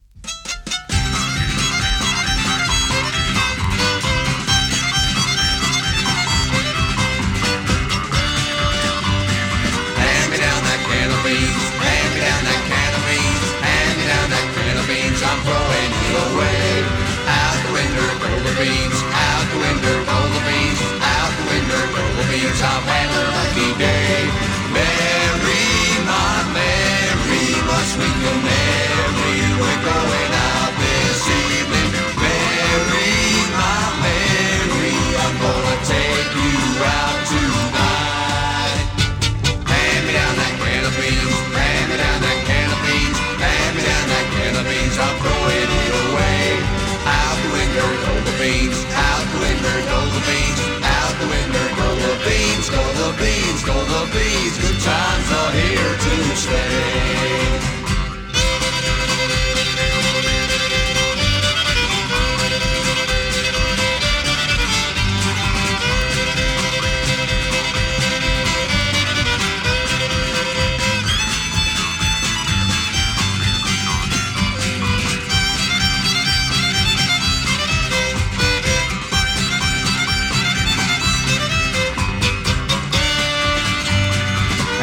試聴 (実際の出品物からの録音です)